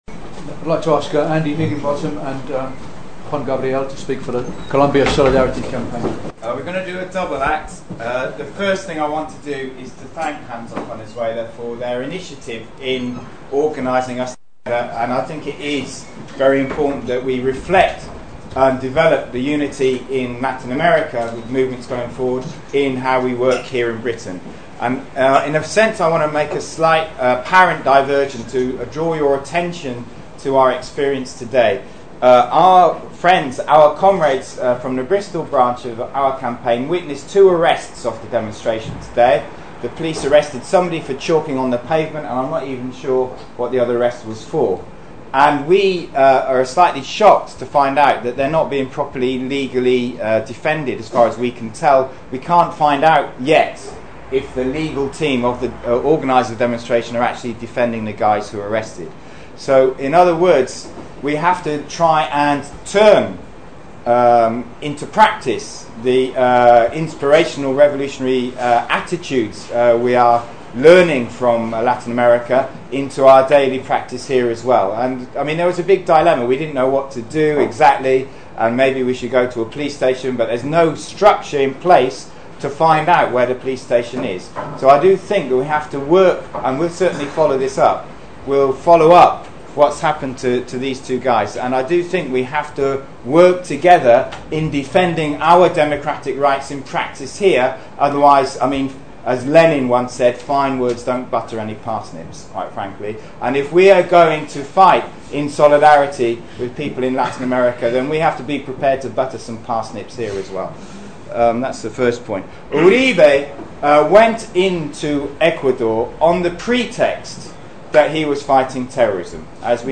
Public meeting with Latin American campaign groups following the violation of Ecuadorian sovereignty by the Colombian Uribe government in March 2008